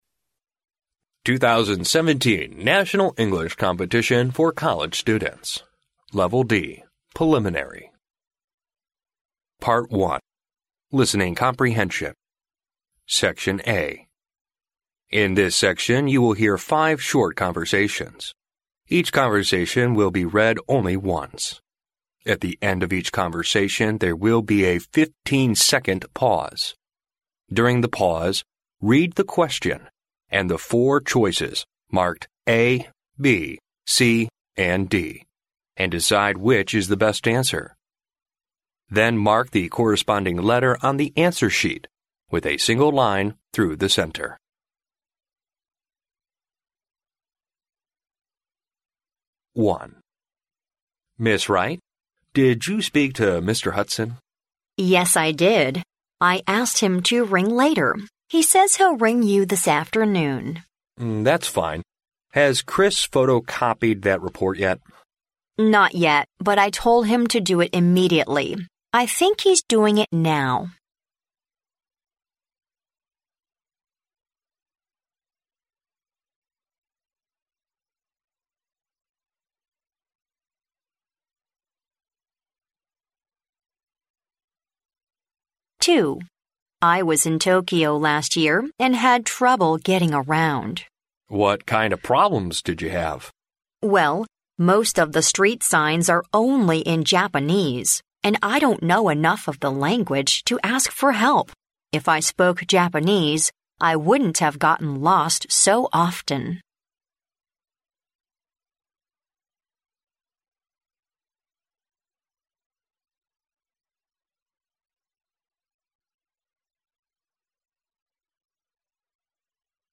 In this section you will hear five short conversations.
At the end of each conversation, there will be a fifteen-second pause.